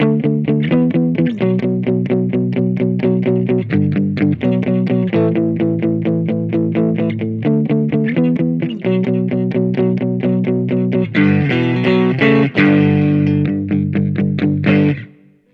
029_RIFF III.wav